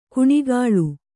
♪ kuṇigāḷu